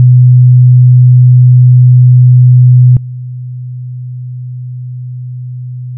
基準音2秒 → 透過損失された音2秒 を聞くことができます。
125Hzの音データ/基準音と低減音 [自動車の低音マフラーなど] 250Hzの音データ/基準音と低減音 [いびきや大型犬の鳴き声など] 500Hzの音データ/基準音と低減音 [会話音[男性の声]など] 1000Hzの音データ/基準音と低減音 [会話音[女性の声]など] 2000Hzの音データ/基準音と低減音[警報音など]